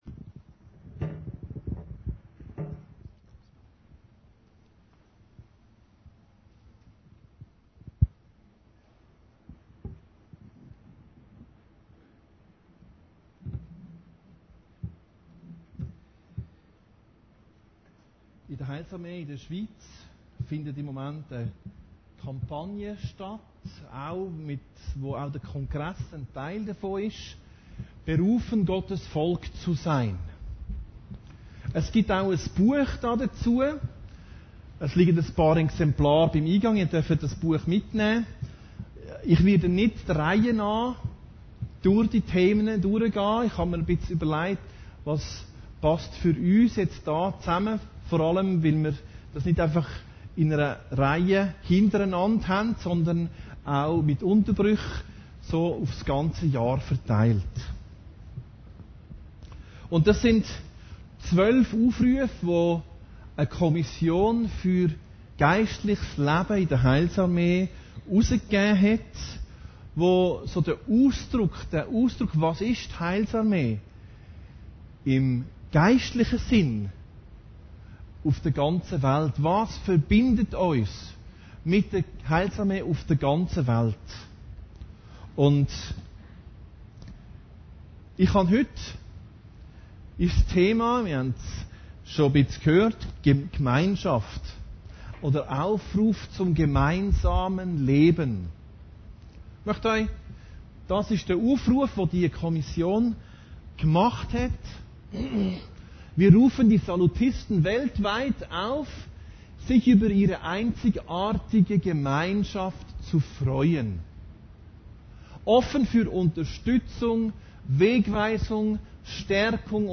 Predigten Heilsarmee Aargau Süd – Aufruf zum gemeinsamen Leben